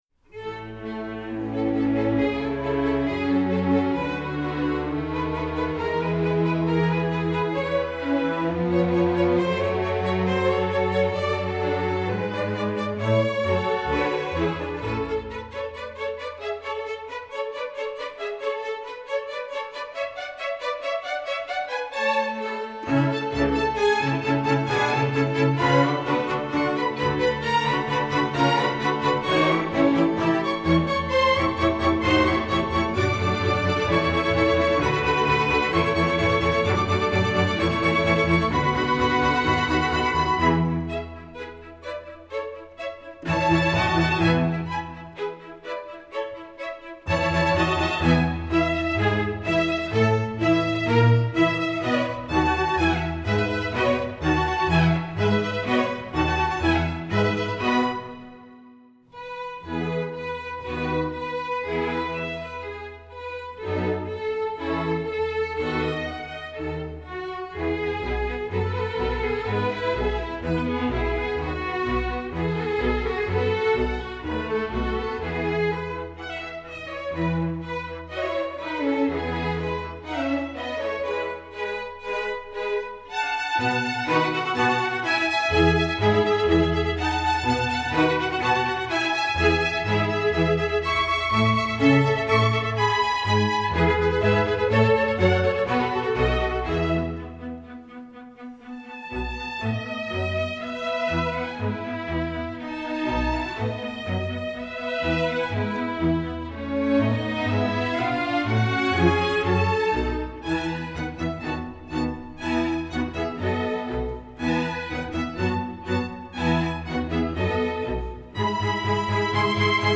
Instrumental Orchestra String Orchestra
String Orchestra